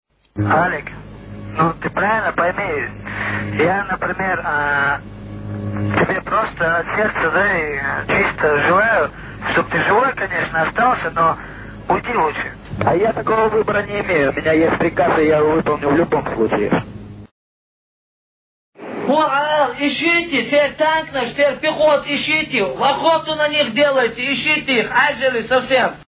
В подвале президентского дворца был развёрнут стационарный радиоузел, откуда дудаевцы выходили в эфир.